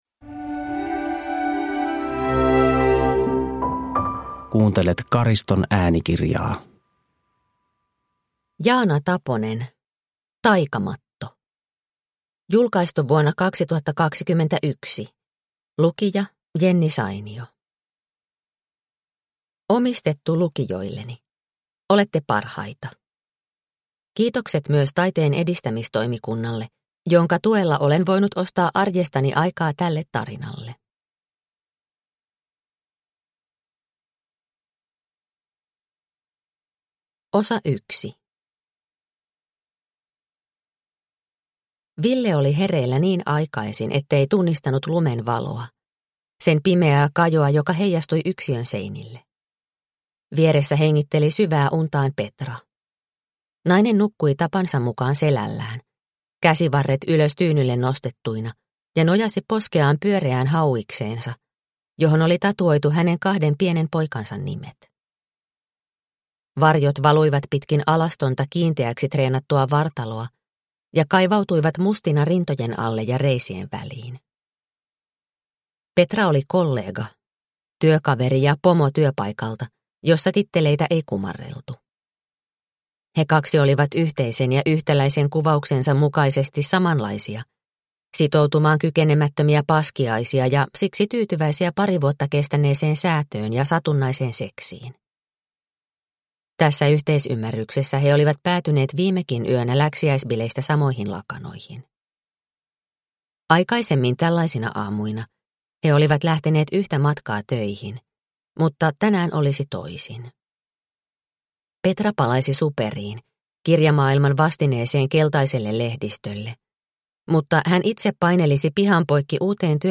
Taikamatto (ljudbok) av Jaana Taponen